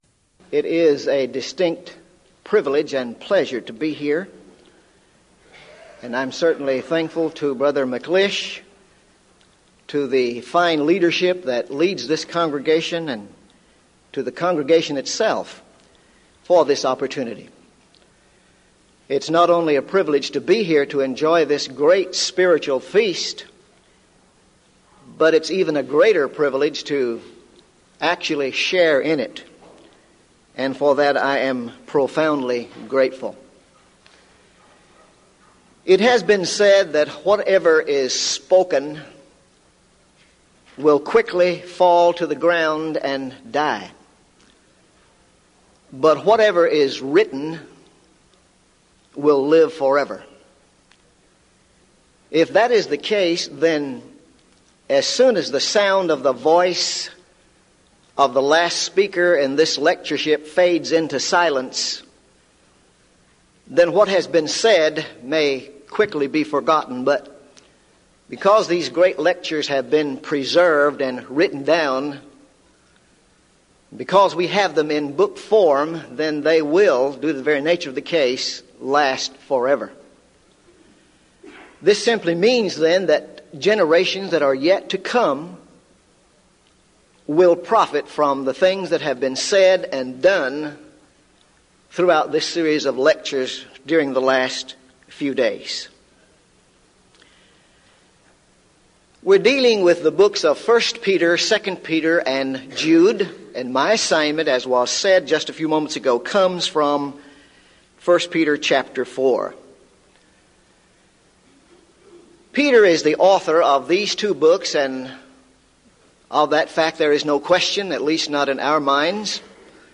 Event: 1998 Denton Lectures Theme/Title: Studies in the Books of I, II Peter and Jude